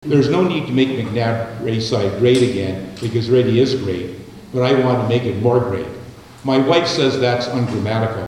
The nominees gathered at McNab School September 22nd for an All-Candidate Forum, hosted by the Greater Arnprior Chamber of Commerce.